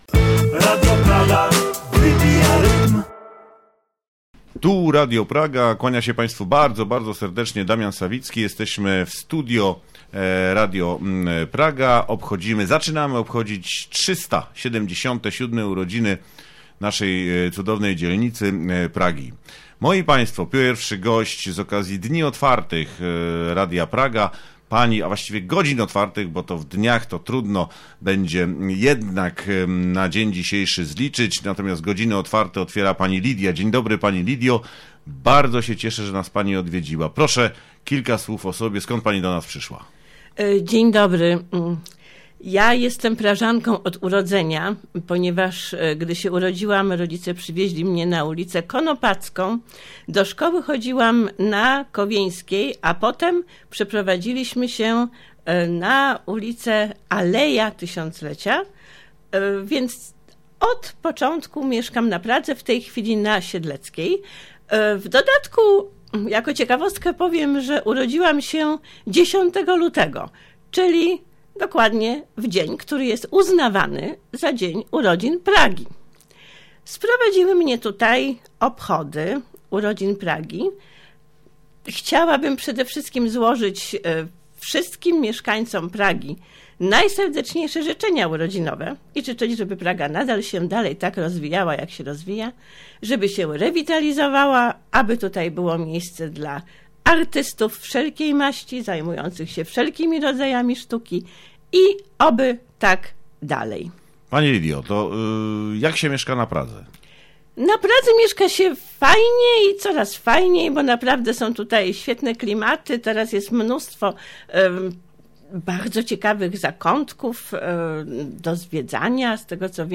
W trakcie naszej porannej sobotniej audycji odwiedzili nas prażanie. Nasi goście byli tak przemili, że opowiedzieli nieco o sobie, złożyli naszej dzielnicy życzenia.